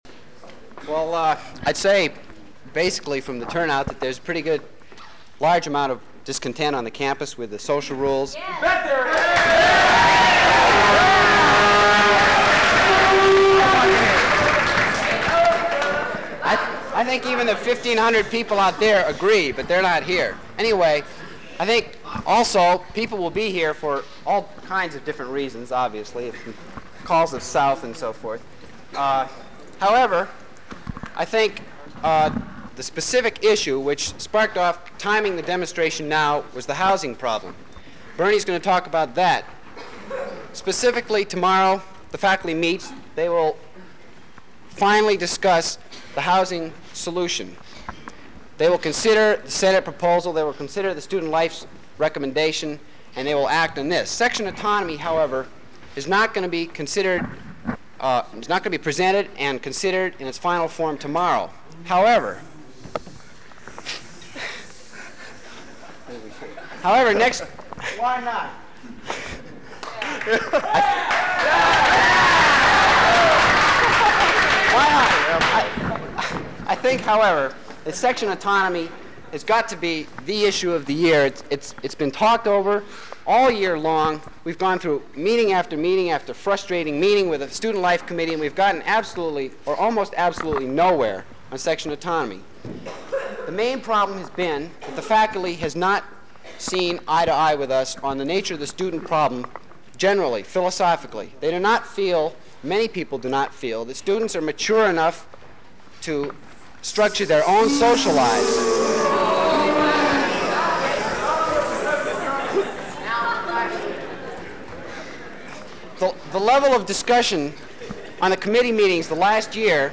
The group retraced their steps, heading north to the center of the campus to the sound of a beating drum.